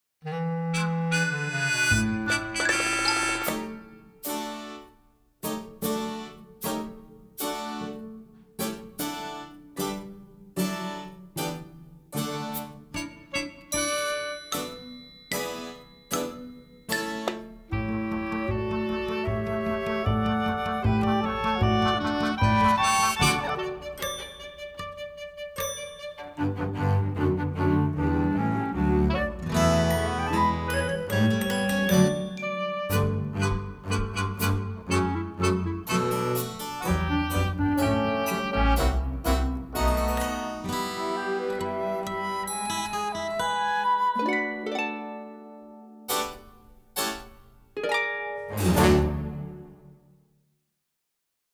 INSTRUMENTAL AUDITION TRACKS - USE FOR RECORDING